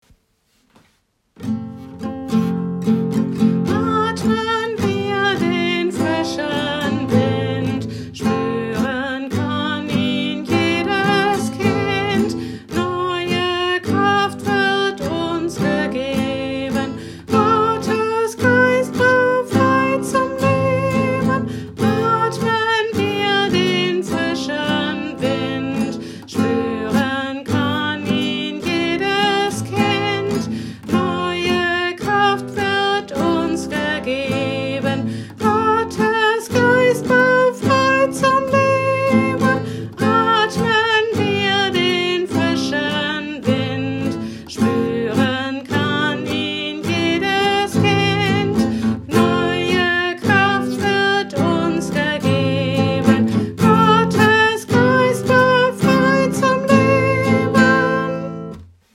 Ein Lied, das im Kindergottesdienst der Kirchengemeinde Wendeburg schon zu Pfingsten und zu anderen Anlässen gesungen wurde, heißt: „Atmen wir den frischen Wind“.
Gitarre